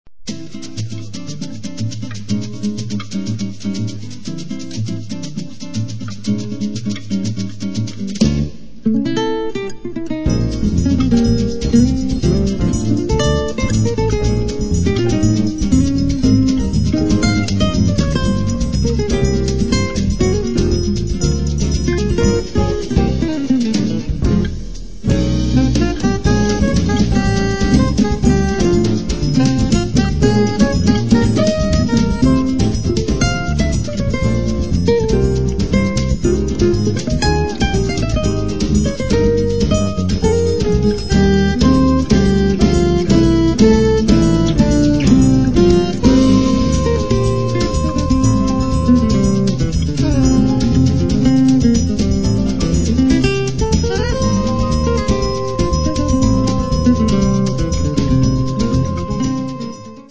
Classical and acoustic guitars
Contralto and soprano sax